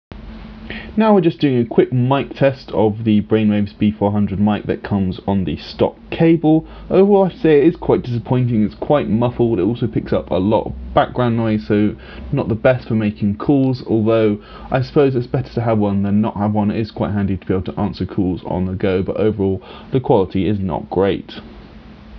The last area to touch on is mic quality – it is perhaps not a big factor in your buying decision, but it is worth testing since the default cable does have an in-built mic for conducting phone calls etc.
It's certainly audible, but the overall sound is very muffled and generally low quality.
mic-test-b400.wav